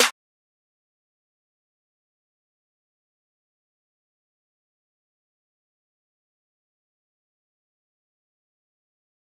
JJSnares (36).wav